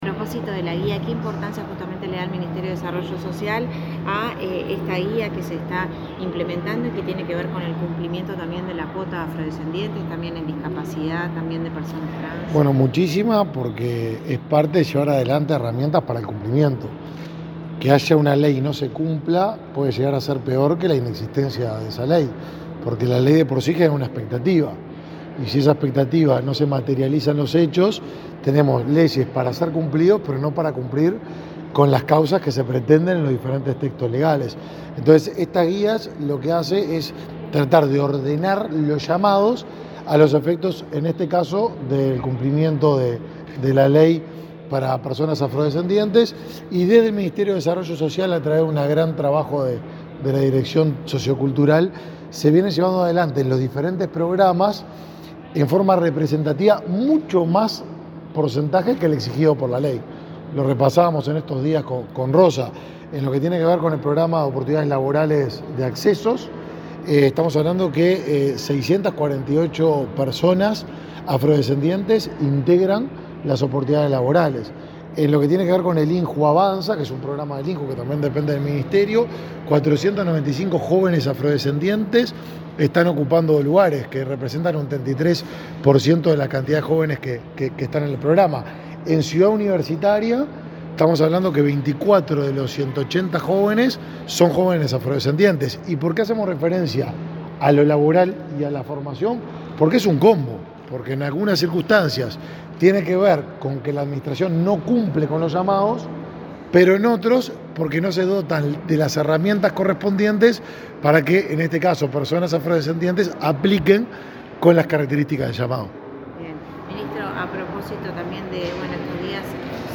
Declaraciones a la prensa del ministro de Desarrollo Social, Martín Lema
Declaraciones a la prensa del ministro de Desarrollo Social, Martín Lema 24/07/2023 Compartir Facebook X Copiar enlace WhatsApp LinkedIn El Ministerio de Desarrollo Social (Mides) y la Oficina Nacional del Servicio Civil (ONSC) presentaron la guía para la implementación de la cuota laboral para afrodescendientes en el ámbito público. El titular de la cartera, Martín Lema, dialogó con la prensa acerca de la importancia de la temática.